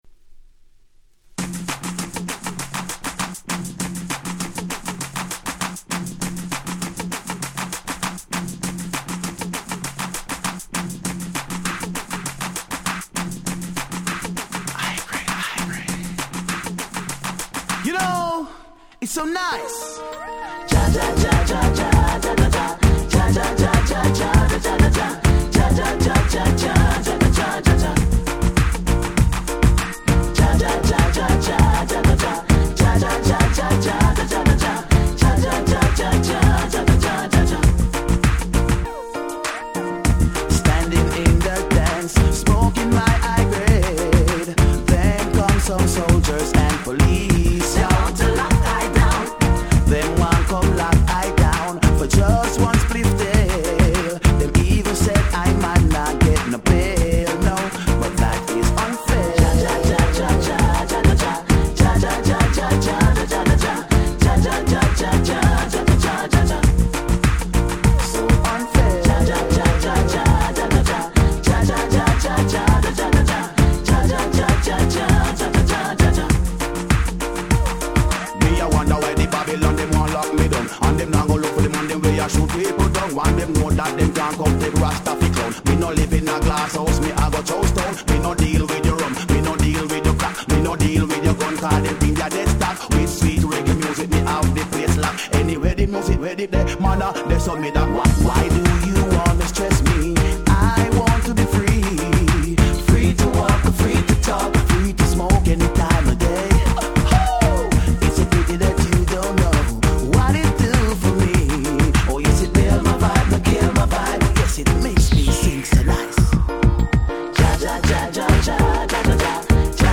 06' Nice Ragga R&B !!
Diwali調のBeatでフロア受け抜群！
サビのコーラスも「チャチャチャチャ〜」と差し替えられ非常に耳に残る中毒性。